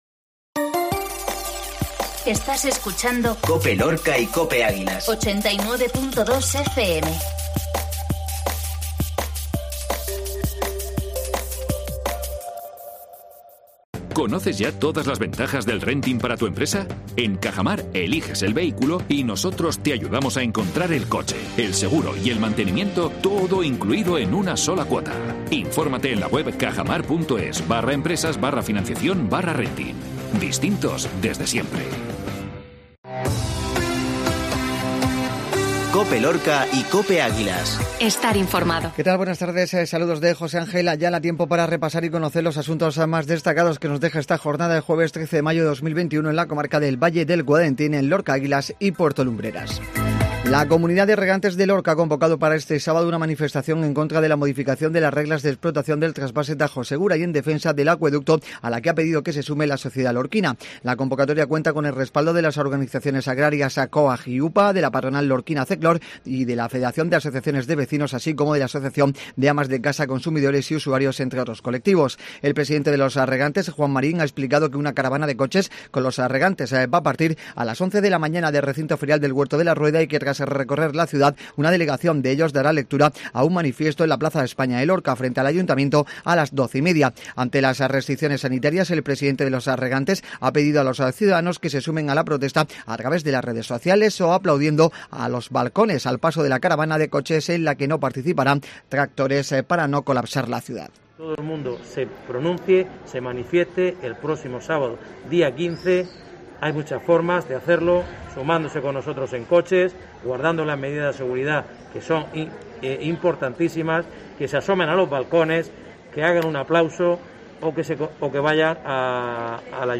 INFORMATIVO MEDIODÍA JUEVES